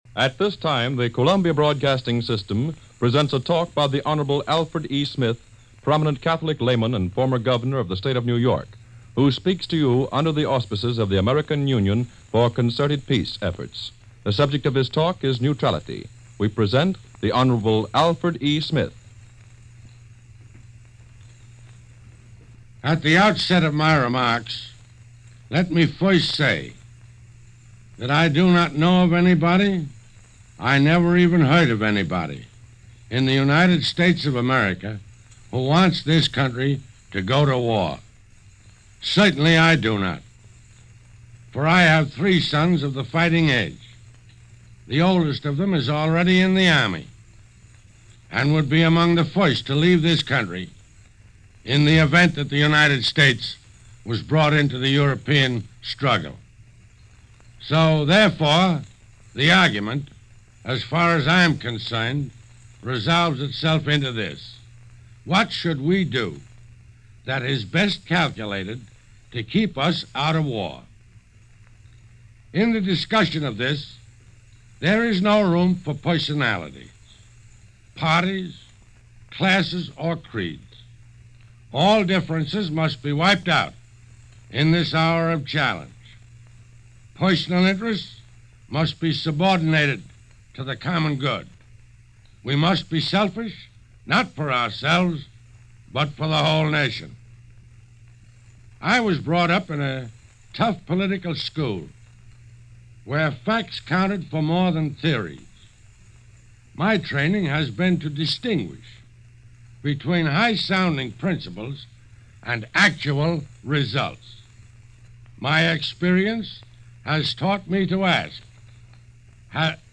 In support of that policy, Alfred E. Smith delivered a nationwide address on October 1, 1939 on the subject of Neutrality. here is the text of that address:
Al-Smith-Neutrality-address-October-1-1939.mp3